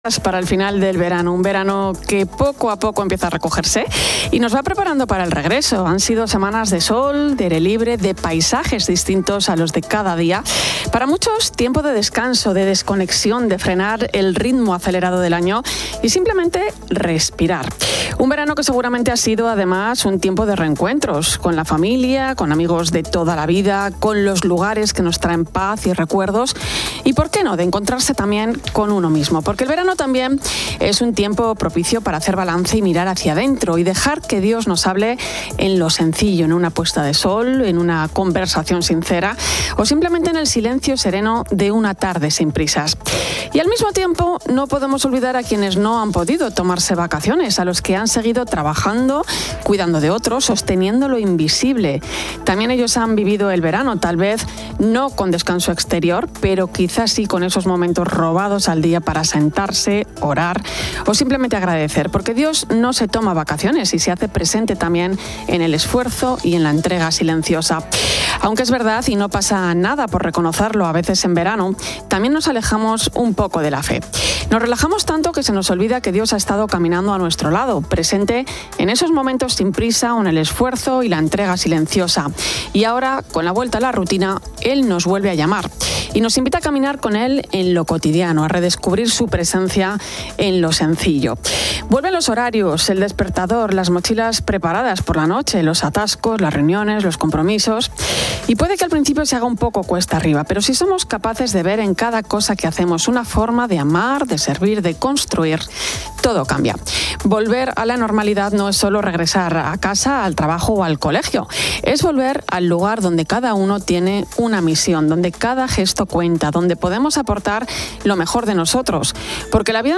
El audio aborda la transición del final del verano y el regreso a la rutina, viéndolo como una oportunidad para la introspección y el reencuentro con lo esencial y con Dios, presente en lo cotidiano y en el esfuerzo. Luego, un segmento de noticias destaca eventos como la reivindicación de la independencia judicial por el Cardenal Cobo, la tragedia de Sudán, el llamado del Papa León XIV a la escucha, la advertencia de Mons. Argüello sobre la IA y el posthumanismo, y las próximas canonizaciones de